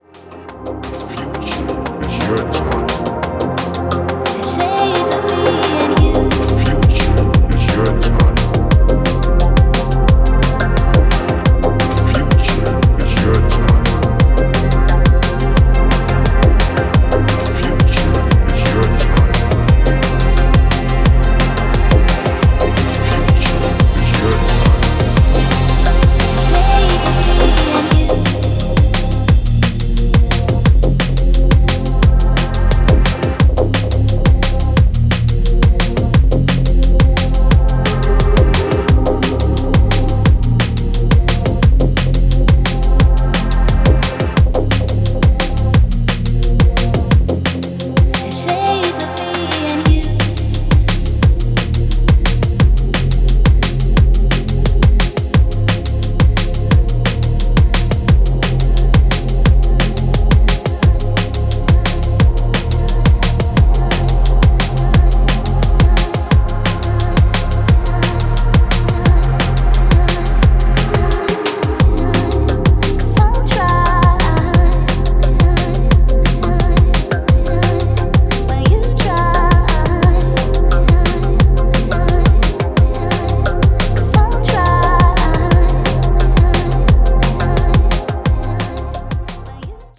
Styl: Drum'n'bass